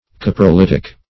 Meaning of coprolitic. coprolitic synonyms, pronunciation, spelling and more from Free Dictionary.
coprolitic.mp3